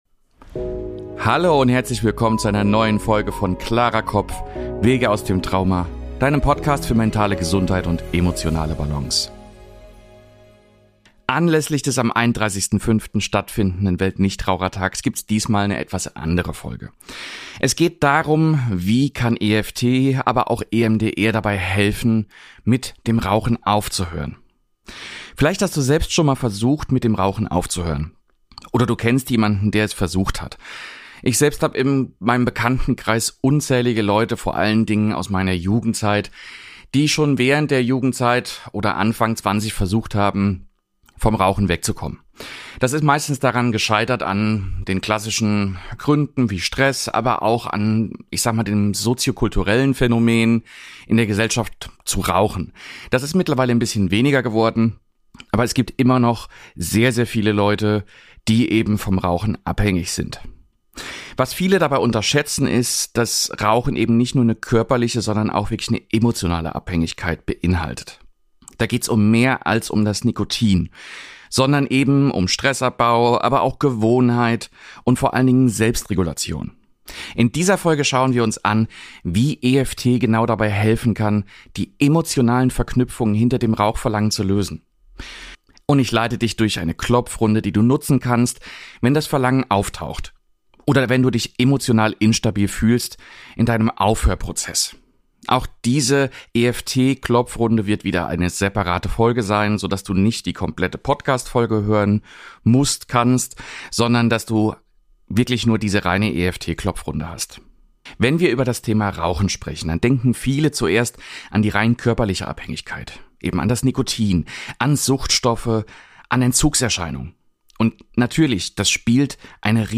Inklusive geführter EFT-Runde zum Mitmachen – bei akutem Verlangen oder als tägliche Begleitung beim Rauchstopp.